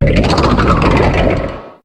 Cri de Sinistrail dans Pokémon HOME.